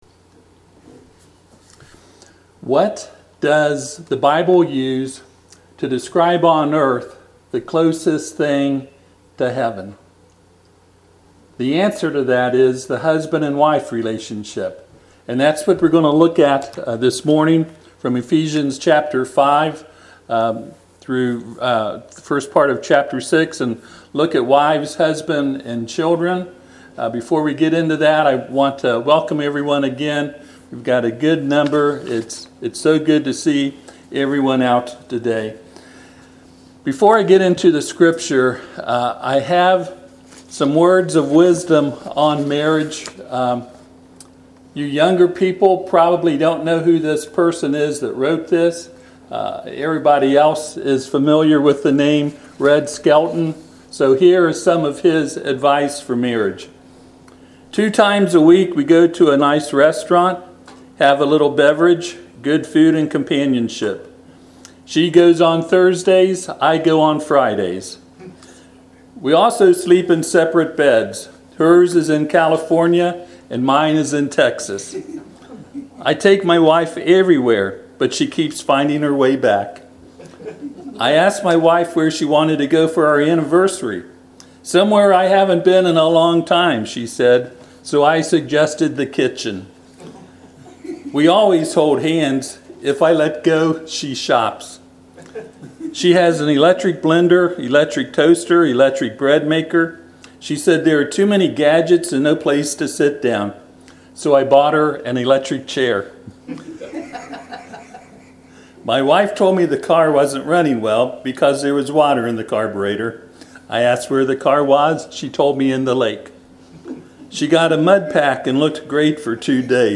Ephesians 5:22-6:4 Service Type: Sunday AM « Revelation